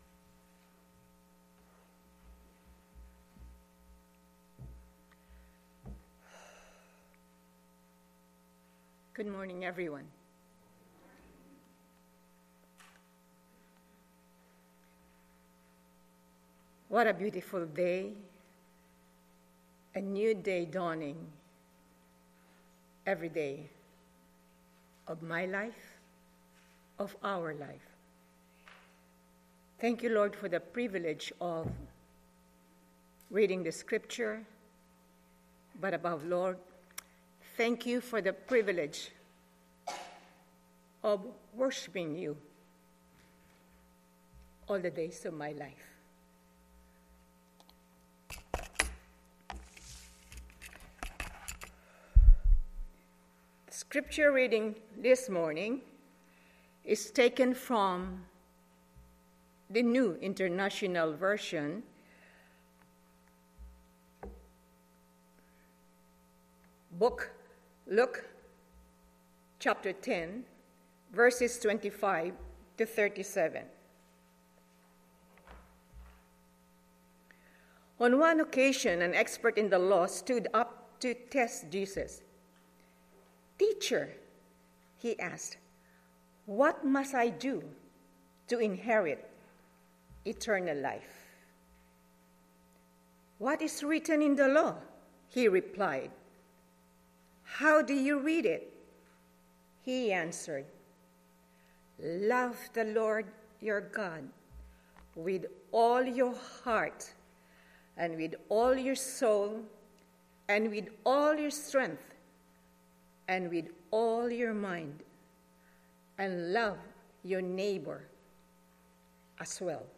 Service Type: 10:30 AM Service